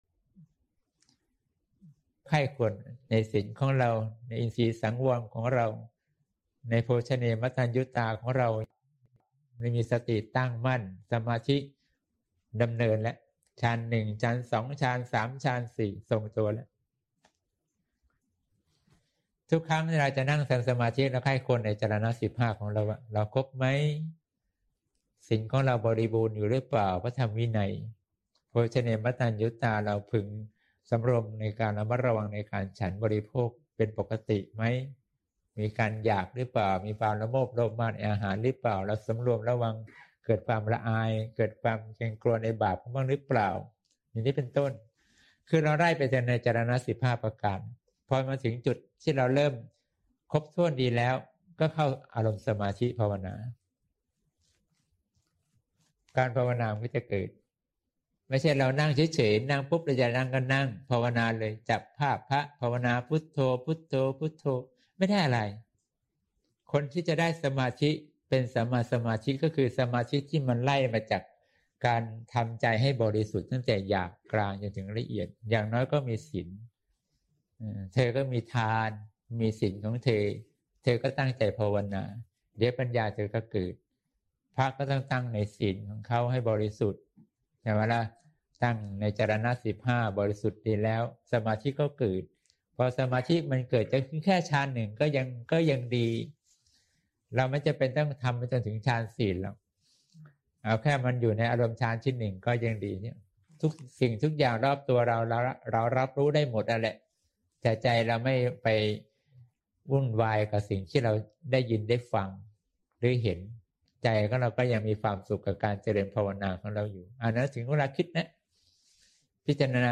เสียงธรรม (เสียงธรรม ๒๙ มิ.ย. ๖๘)